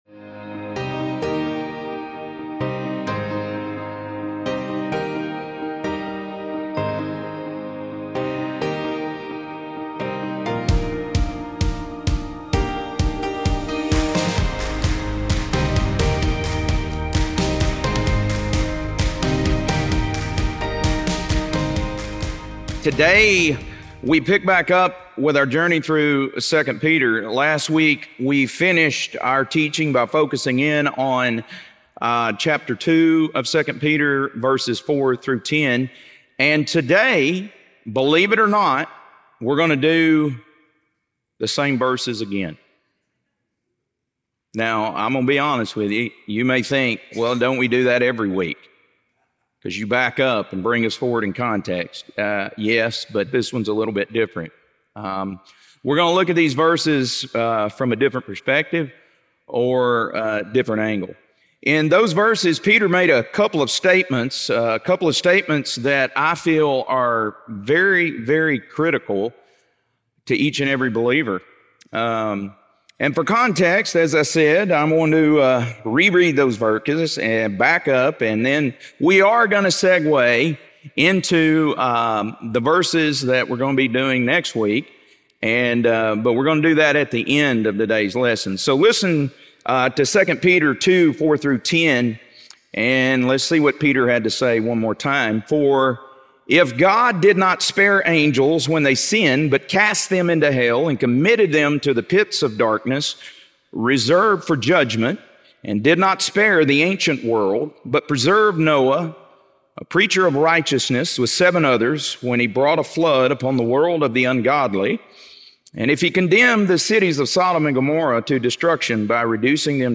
2 Peter - Lesson 2C | Verse By Verse Ministry International